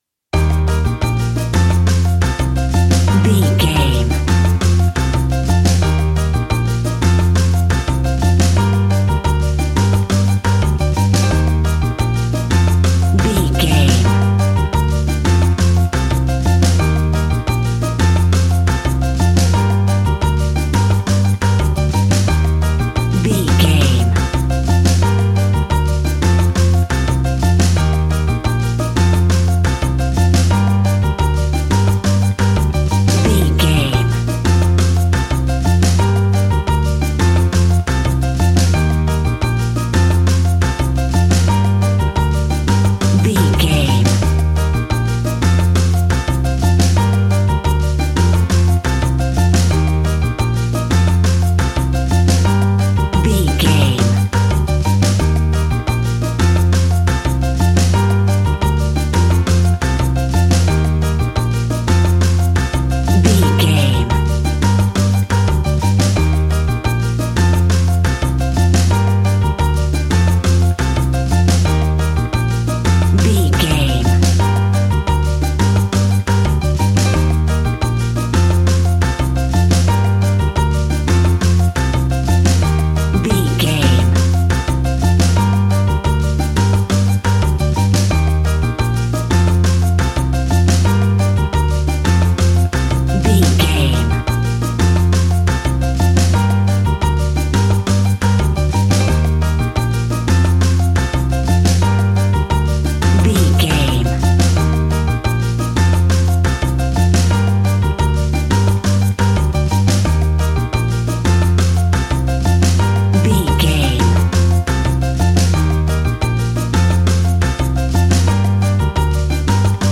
An exotic and colorful piece of Espanic and Latin music.
Aeolian/Minor
flamenco
instrumentals
romantic
maracas
percussion spanish guitar